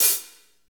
HAT S S H0LR.wav